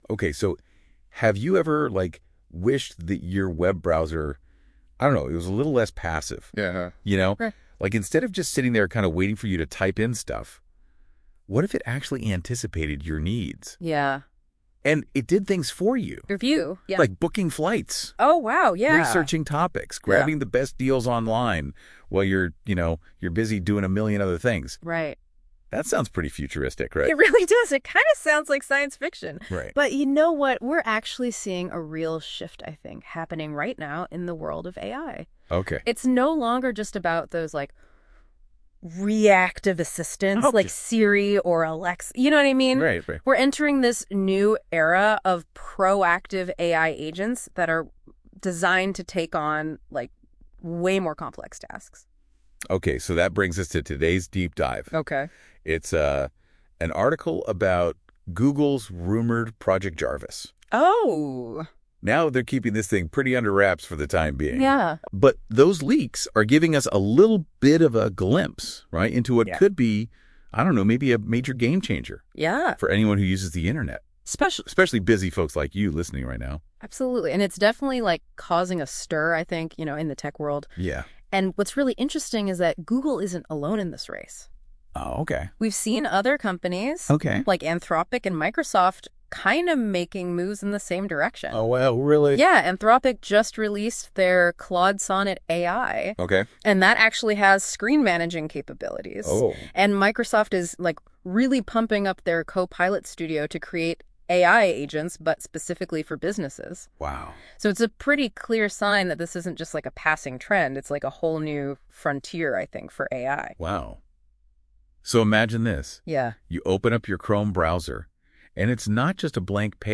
Listen to the podcast version of this blog post, created with Notebook LM, for deeper insights and practical takeaways: